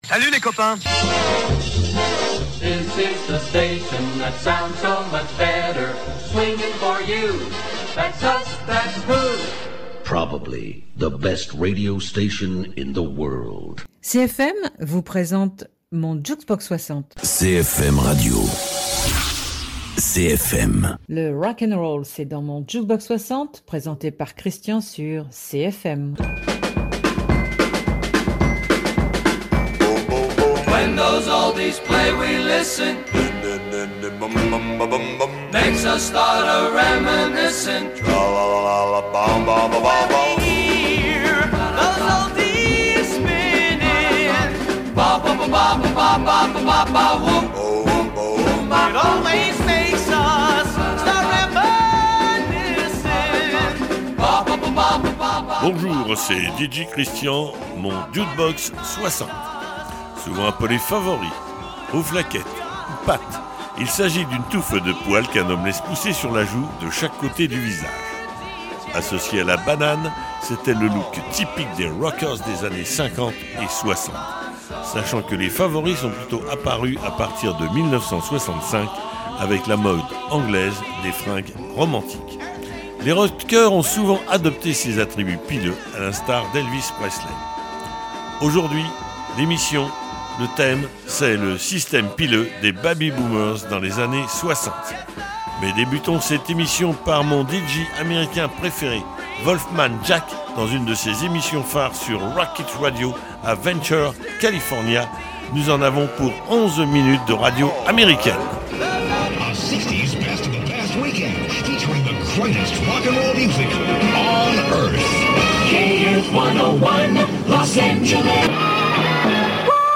Invité(s) : Wolfman Jack le DJ d’Américan Graffiti, nous fait une démonstration de son talent durant 11 minutes.